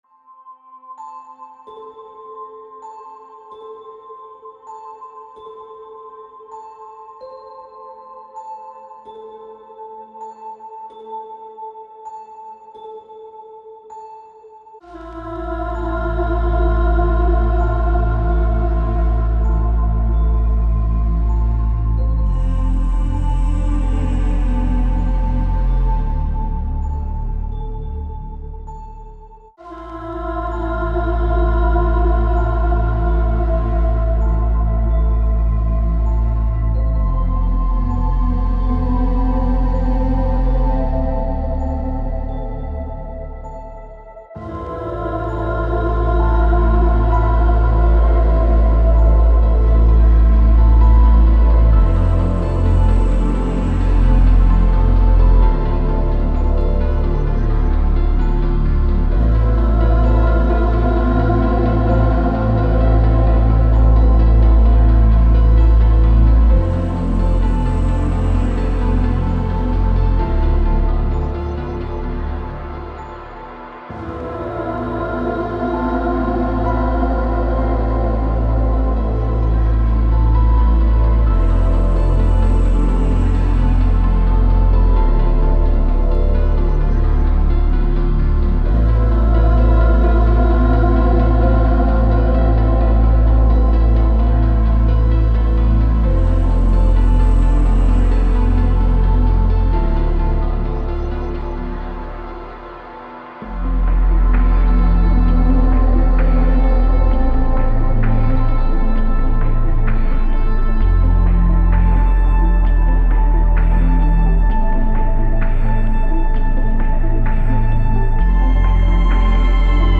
Techno ambient music